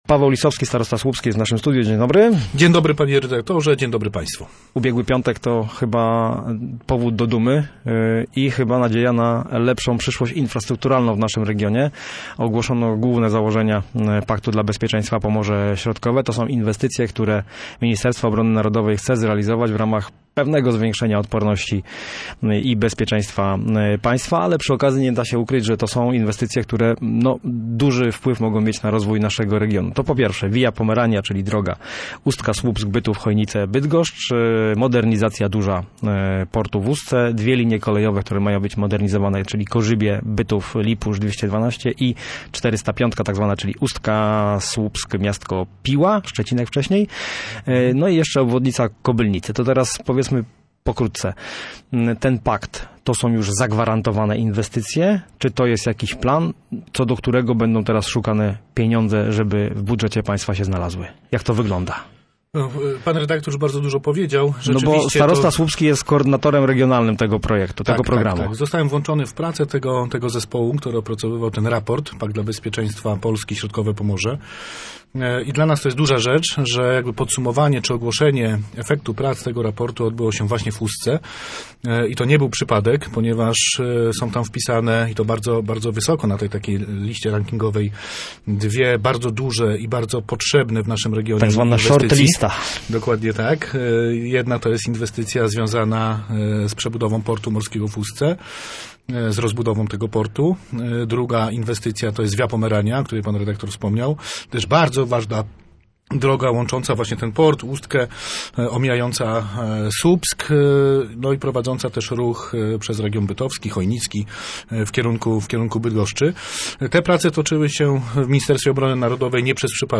O inwestycjach zapisanych w Pakcie dla Bezpieczeństwa i ich znaczeniu dla Pomorza Środkowego mówił na antenie Radia Gdańsk starosta słupski
Lisowski__rozmowa_OK.mp3